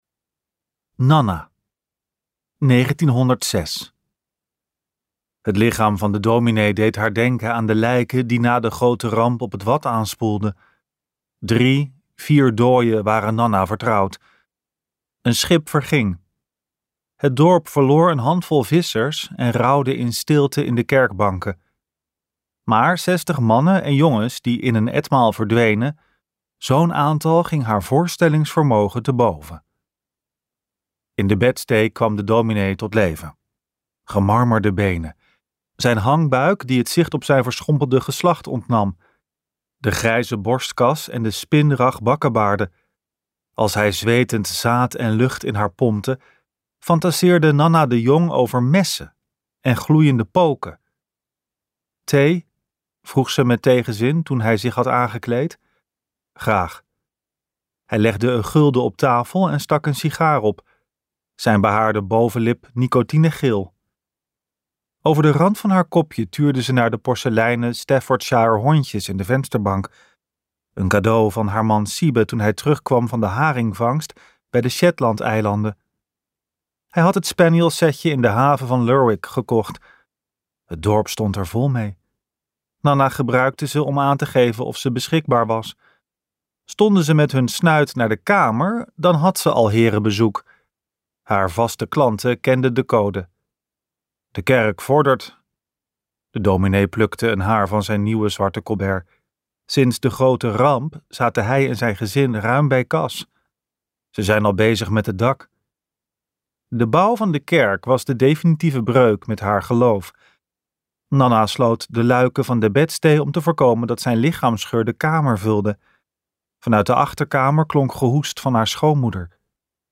Ambo|Anthos uitgevers - Wondermond luisterboek